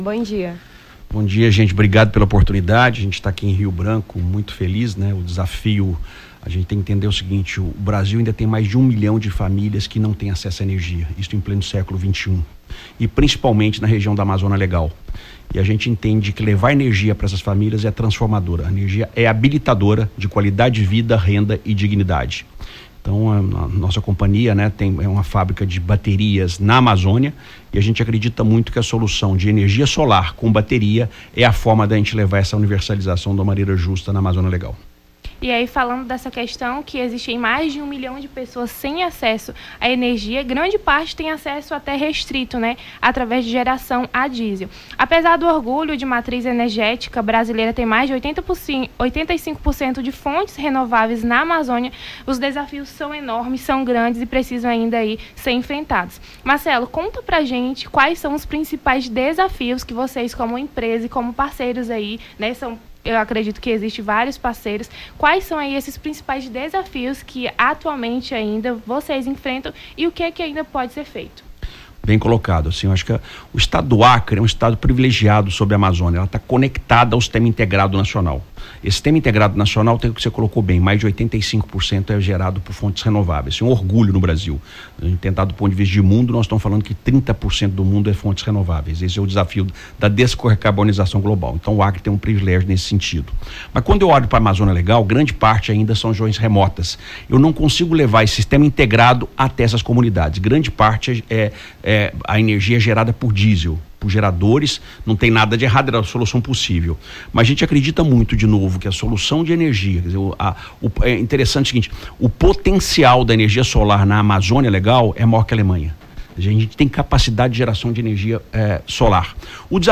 Nome do Artista - CENSURA - ENTREVISTA (ENERGIAS DA AMAZONIA) 23-11-23.mp3